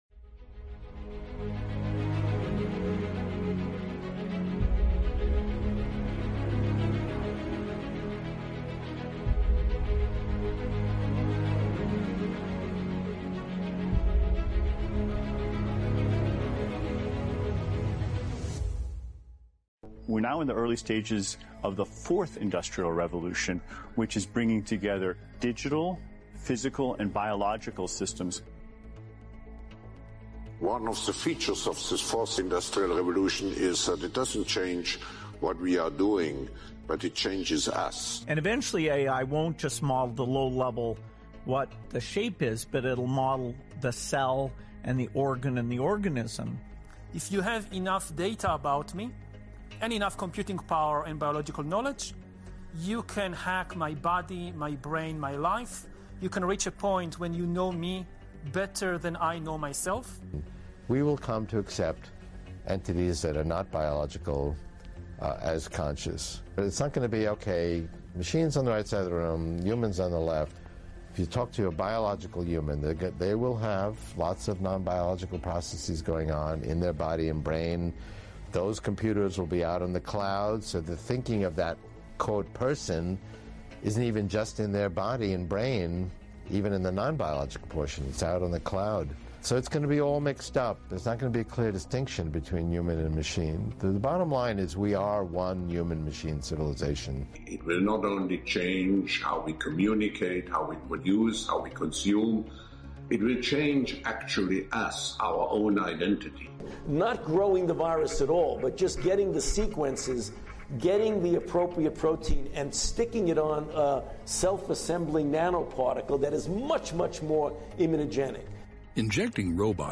AI Cyber Satan and Technocracy - Conversation